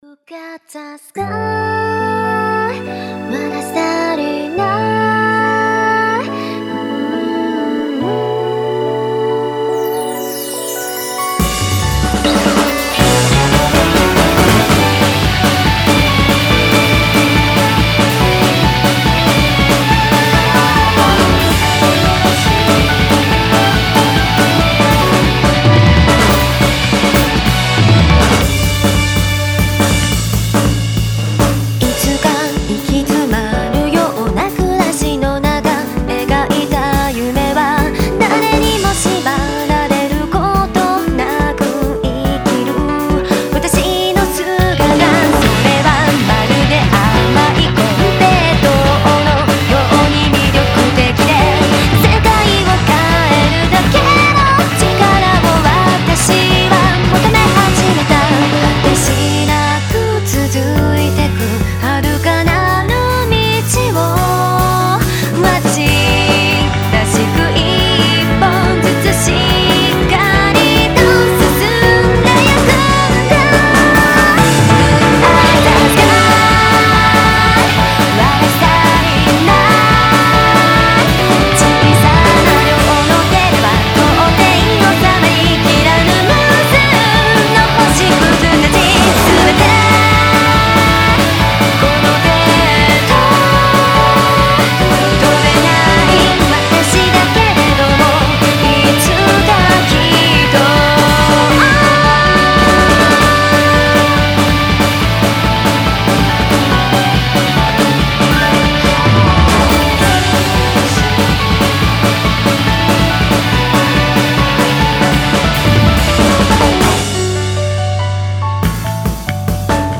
東方マルチジャンルアレンジアルバムです。
ボーカル・インストスタイルはいつもながら、静かに聞ける曲、ノリノリな曲、色々と取り揃えております！
ロック・ジャズ・ちょっと不思議なクラブ風味・今までにない曲調もあったりします。